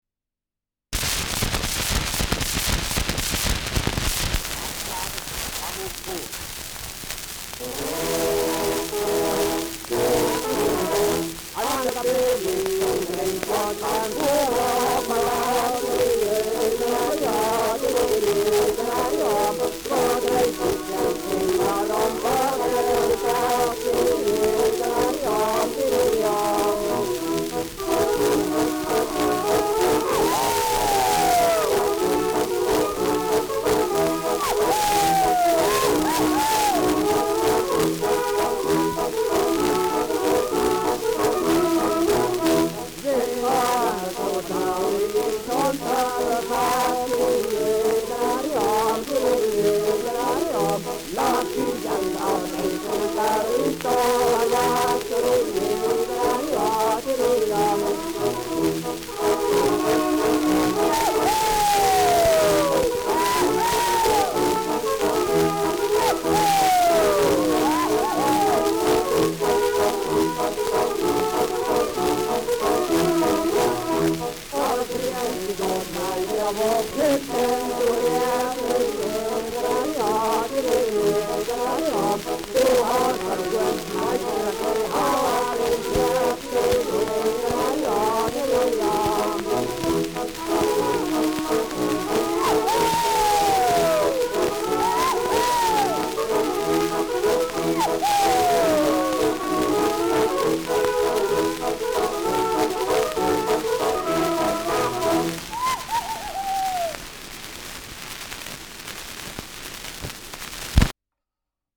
An der böhmischen Grenz : Ländler mit Gesang [An der böhmischen Grenze : Ländler mit Gesang]
Schellackplatte
Starkes Grundrauschen : Gelegentlich leichtes bis stärkeres Knacken : Verzerrt an lauteren Stellen : Leiern
Militärmusik des k.b. 7. Feldartillerie-Regiments, München (Interpretation)